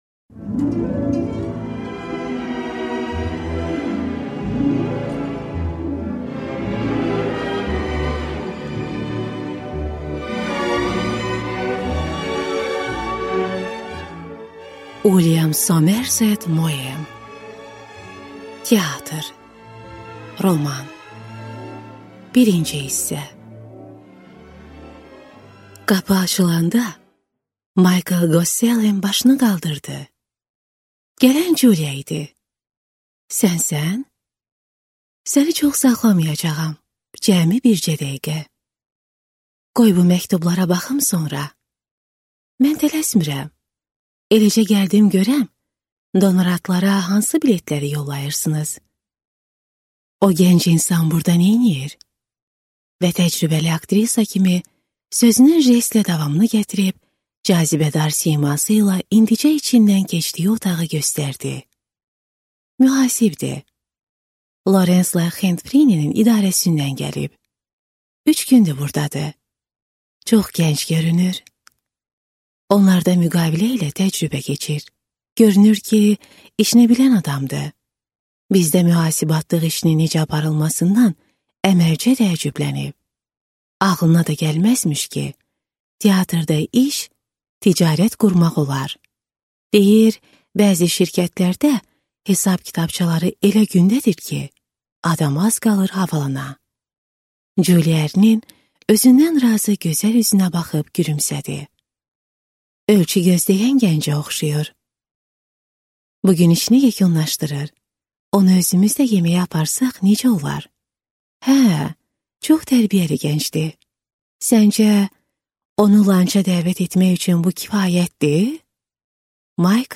Аудиокнига Teatr | Библиотека аудиокниг